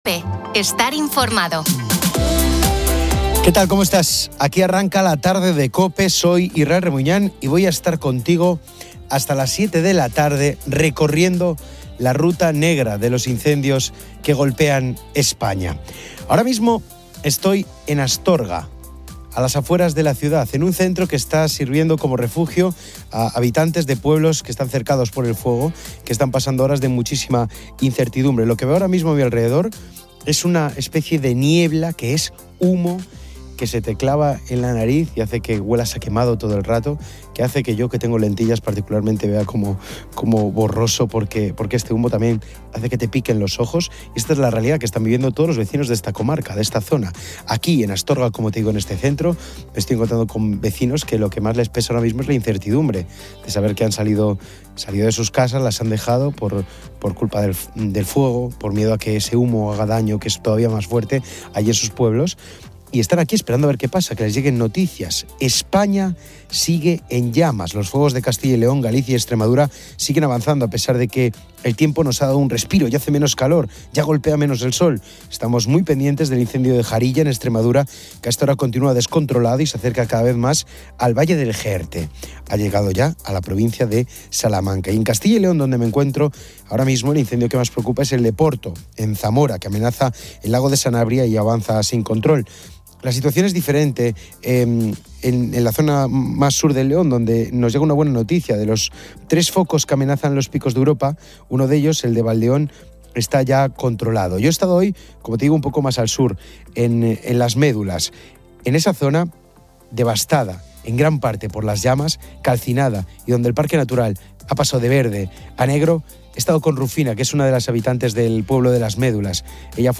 El programa también inserta cortes de anuncios y promociones.